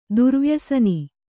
♪ durvyasani